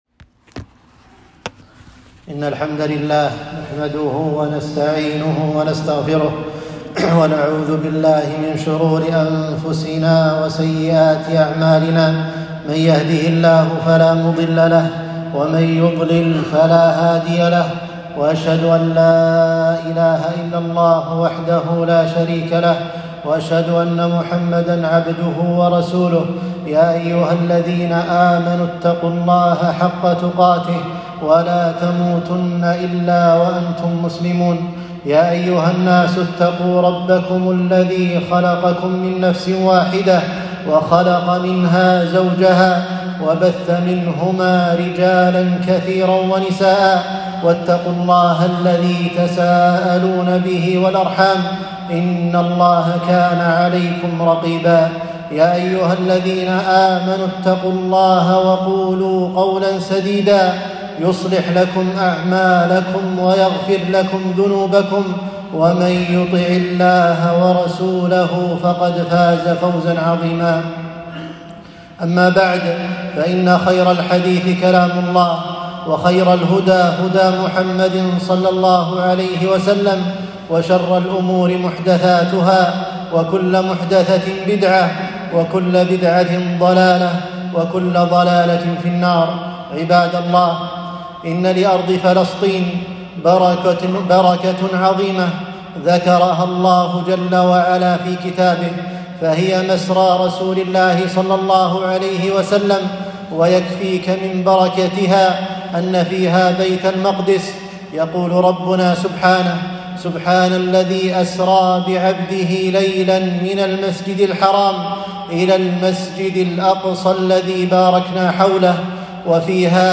خطبة - فلسطين قضية إسلامية - دروس الكويت